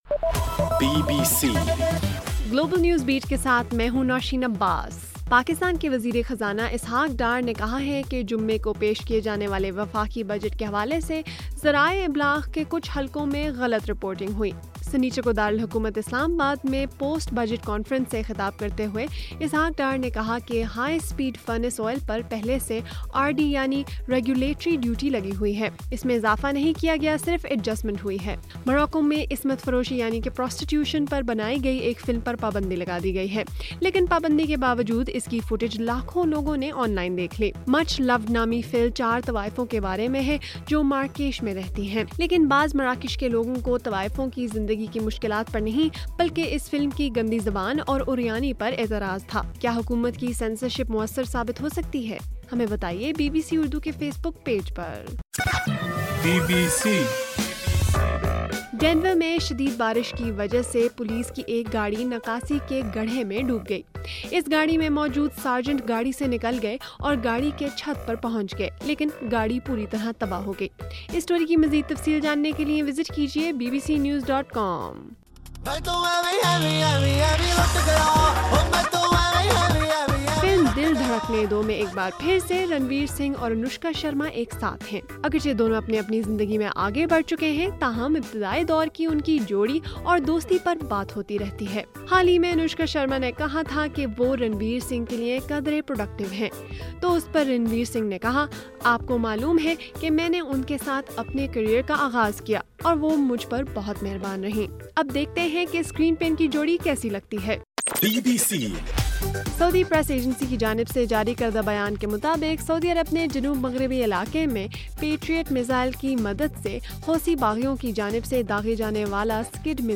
جون 6: رات 9 بجے کا گلوبل نیوز بیٹ بُلیٹن